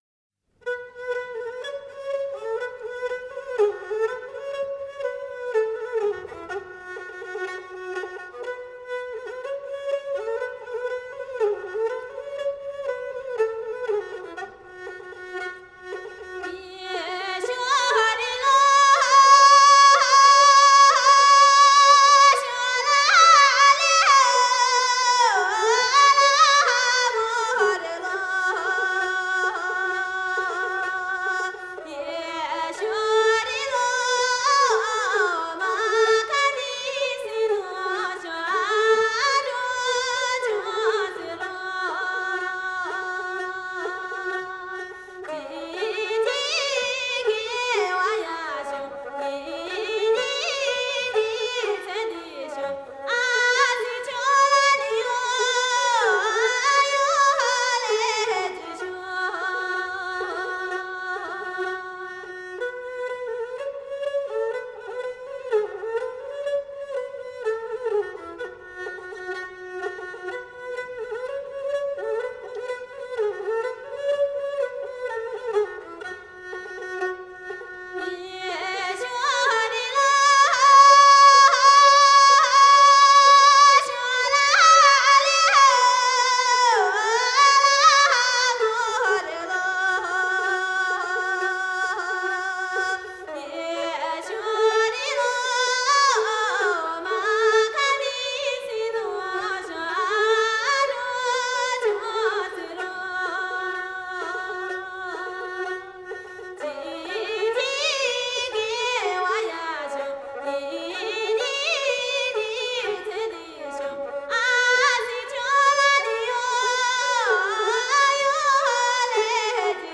少数民族音乐系列
29首歌，旋律朴实悦耳，歌声高吭开怀，
都有嘹亮的歌声，一流的技巧。